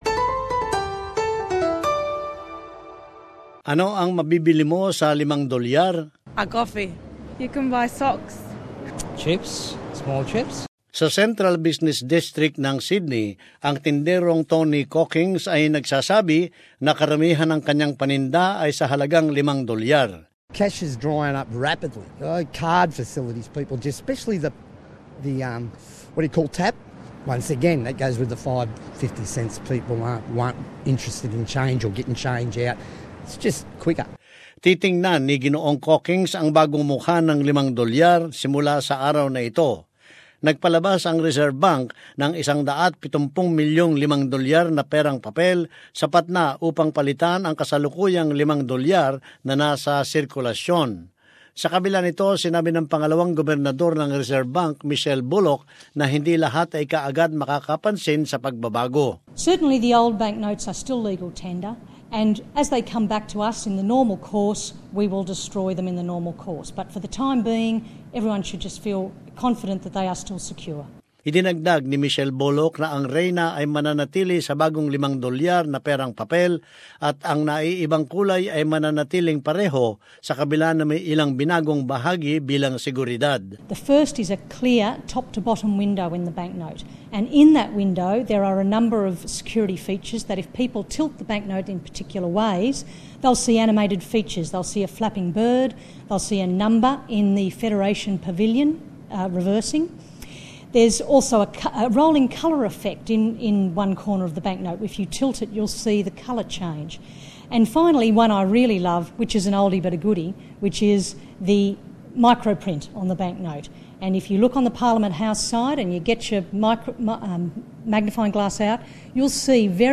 As this report shows, the move is an attempt to beat counterfeiters, aiming to make the country's currency the most secure in the world.